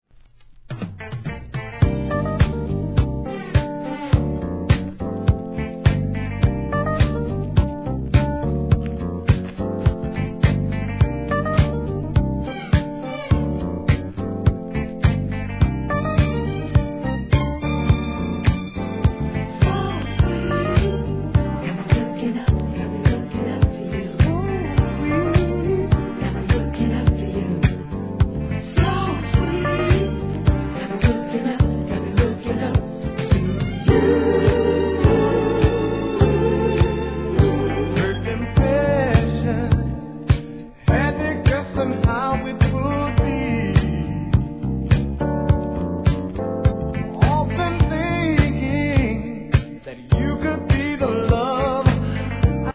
¥ 770 税込 関連カテゴリ SOUL/FUNK/etc...
DANCE CLASSIC!!